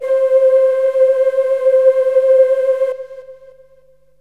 SYNTH GENERAL-1 0008.wav